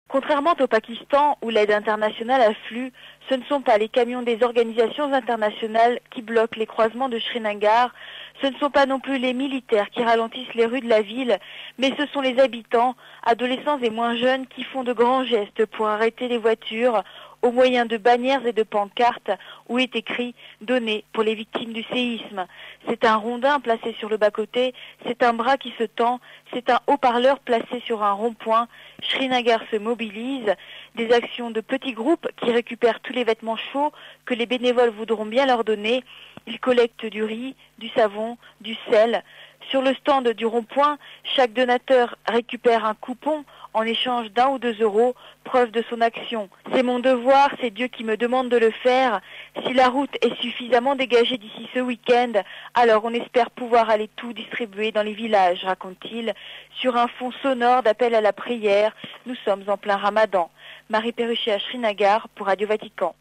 Le reportage
à Srinagar dans le Cachemire indien RealAudio